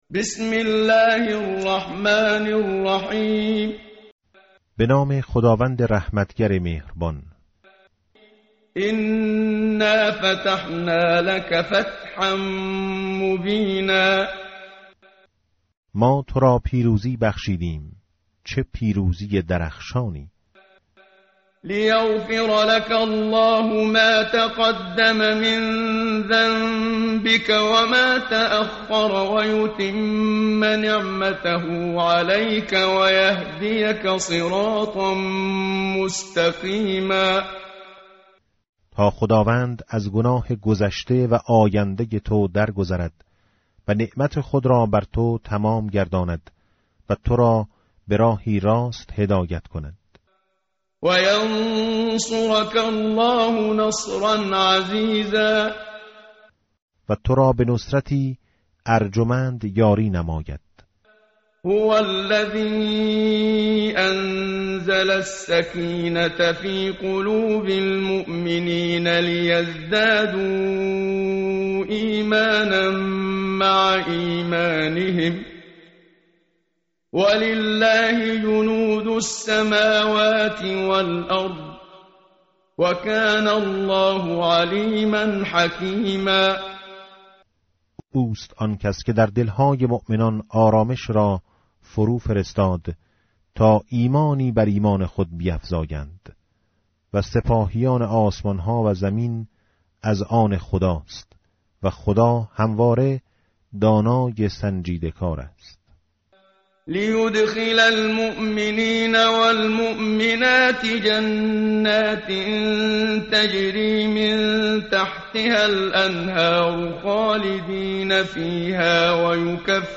متن قرآن همراه باتلاوت قرآن و ترجمه
tartil_menshavi va tarjome_Page_511.mp3